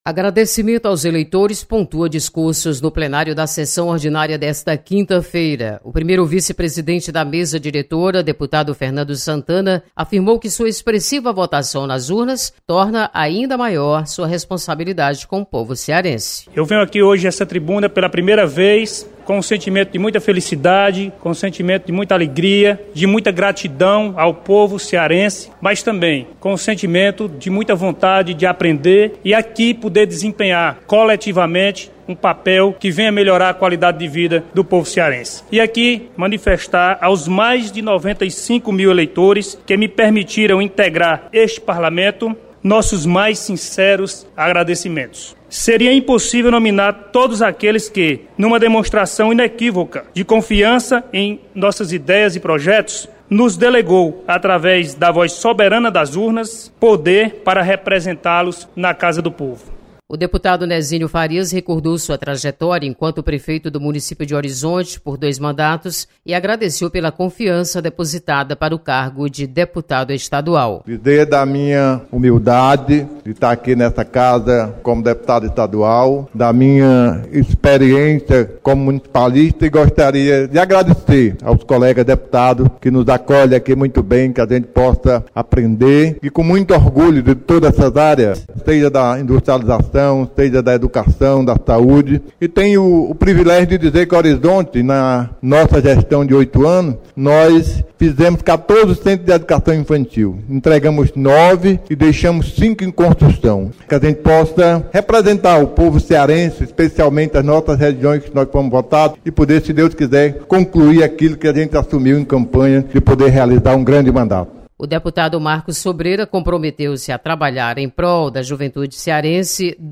Deputados de primeiro mandato agradecem aos eleitores e falam sobre suas metas de mandato. Repórter